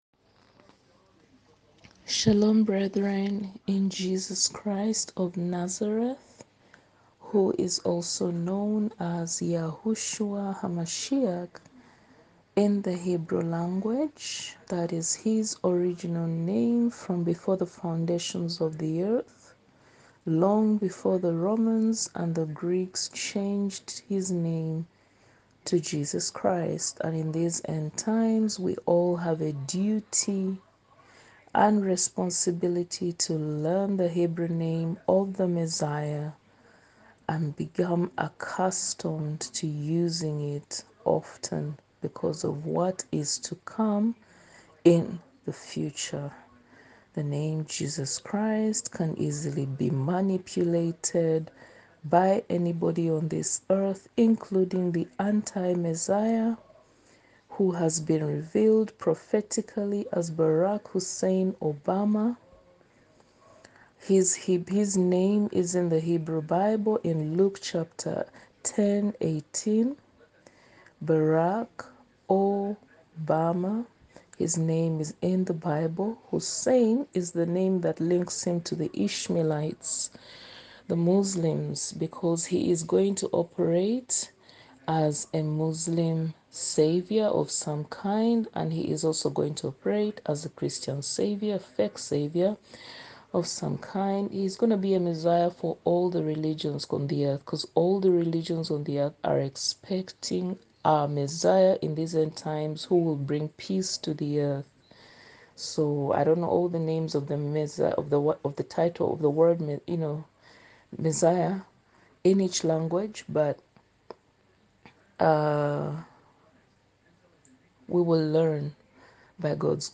*Audio Teaching*